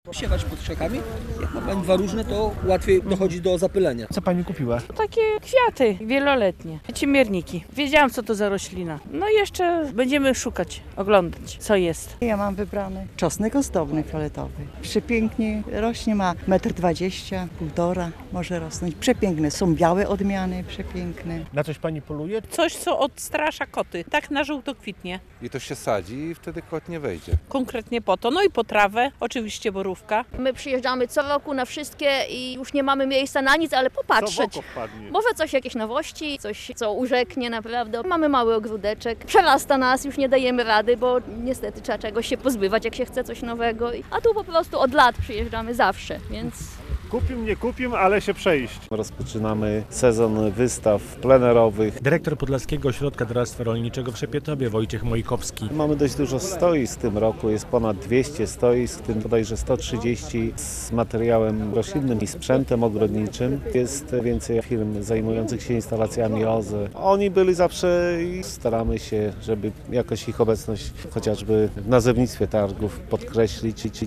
Targi ogrodnicze w Szepietowie - moc sadzonek krzewów owocowych i roślin rabatowych